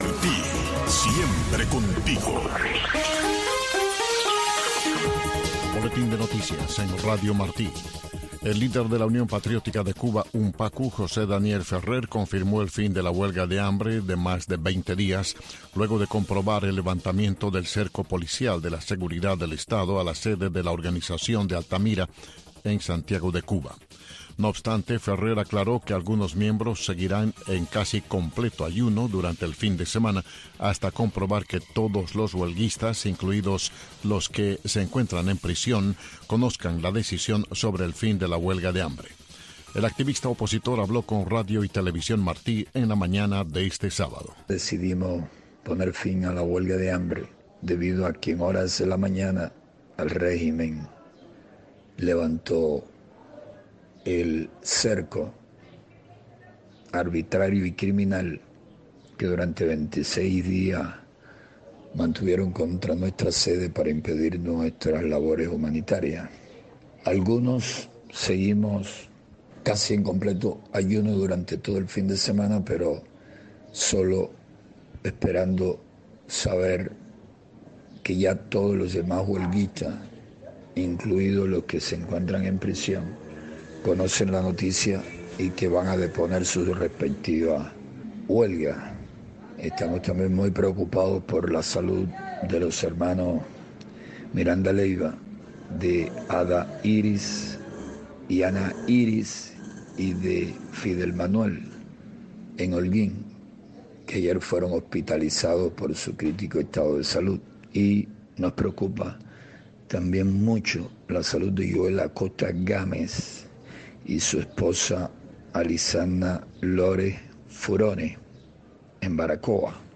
Entrevistas e informaciones con las voces de los protagonistas desde Cuba.